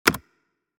Racing, Driving, Game Menu, Ui Select Sound Effect Download | Gfx Sounds
Racing-driving-game-menu-ui-select.mp3